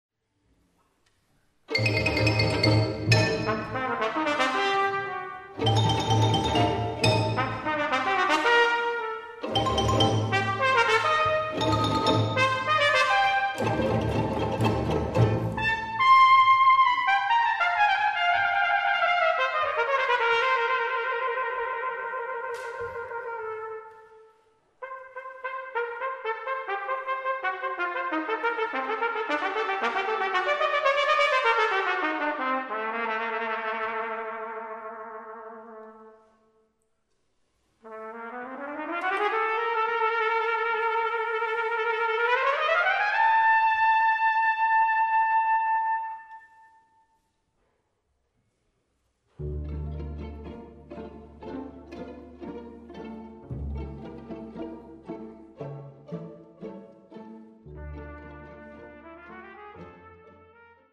Besetzung: Instrumentalnoten für Trompete